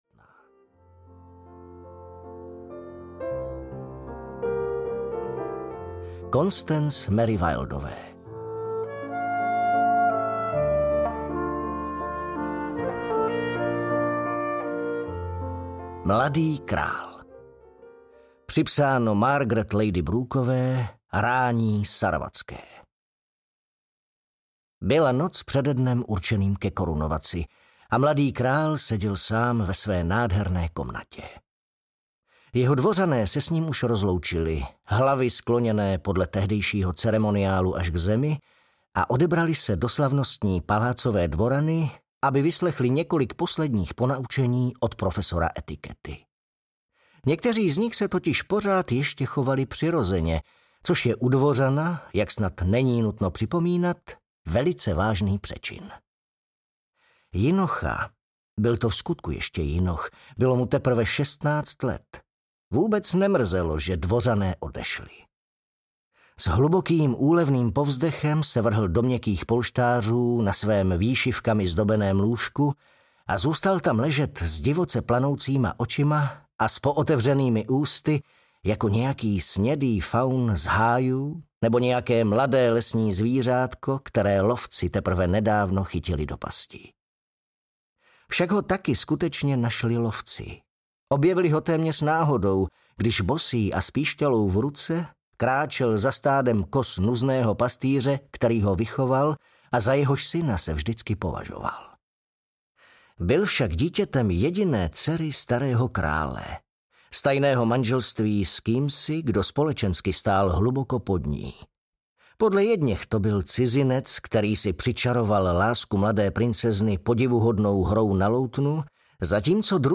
Dům granátových jablek audiokniha
Ukázka z knihy